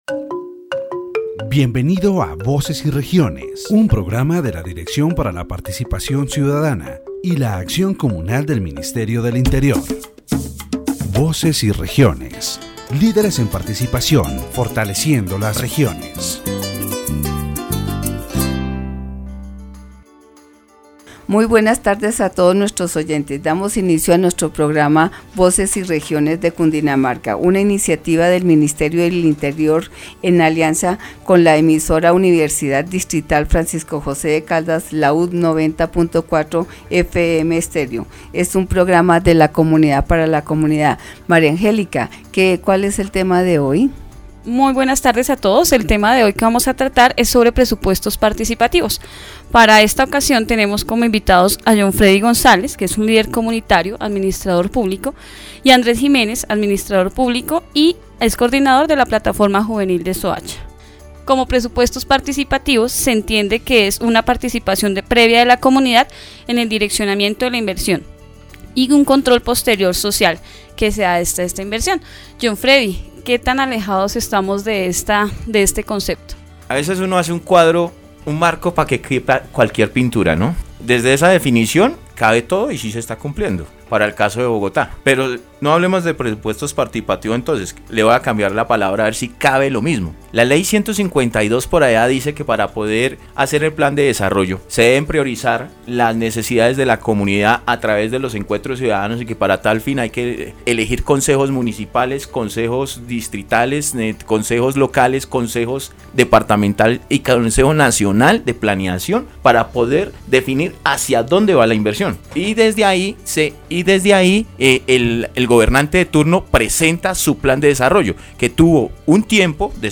The radio program "Voices and Regions" of the Directorate for Citizen Participation and Communal Action of the Ministry of the Interior focuses on participatory budgets in the Department of Cundinamarca.